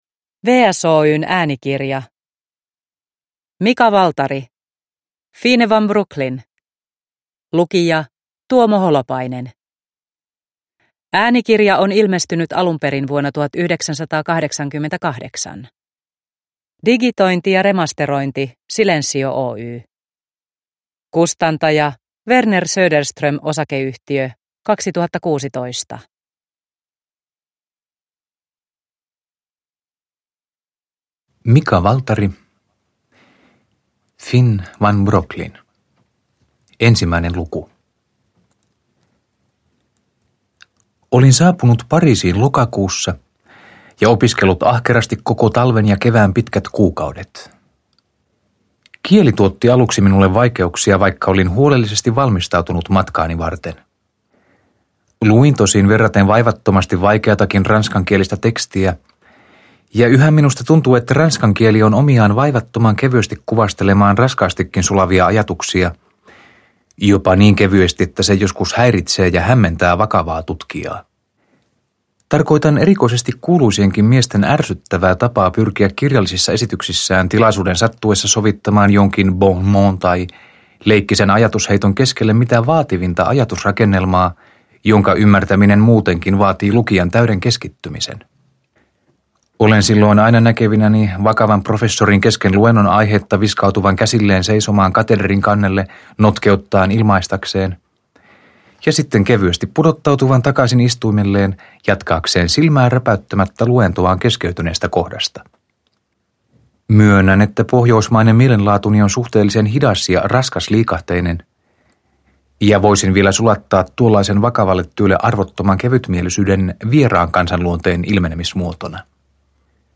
Fine van Brooklyn – Ljudbok – Laddas ner